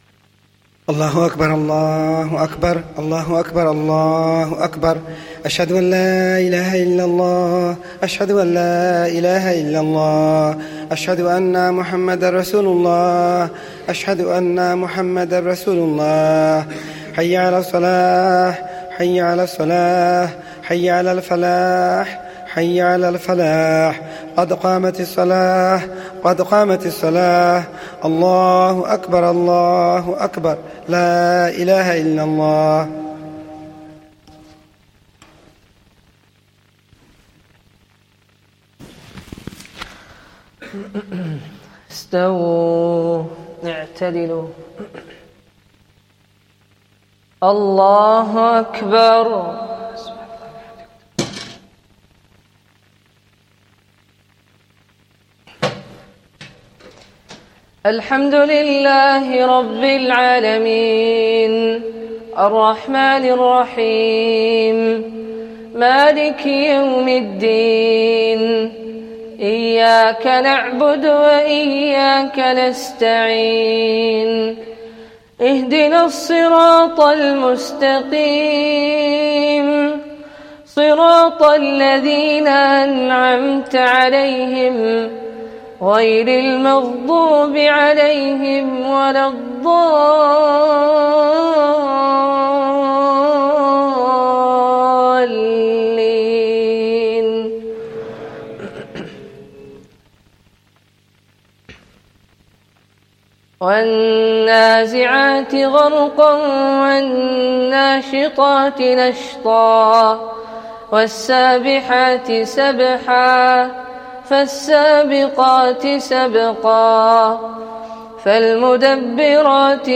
Esha Jammat